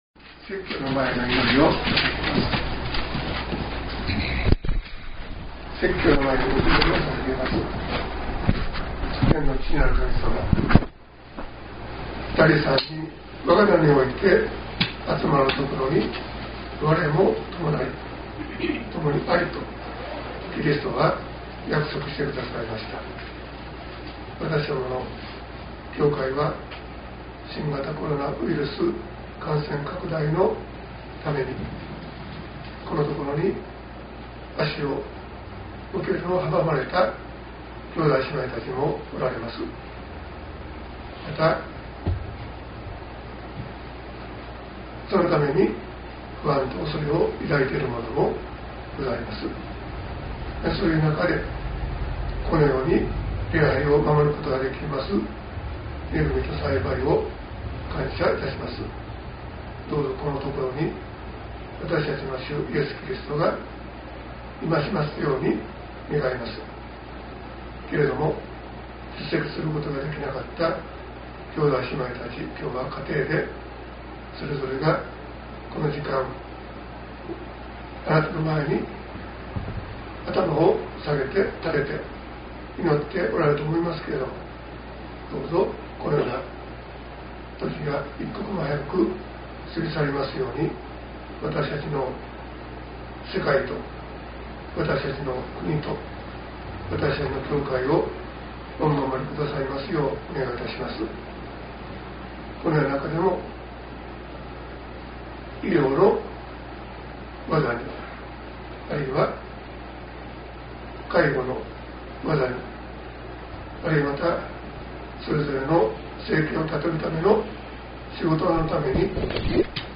説教要旨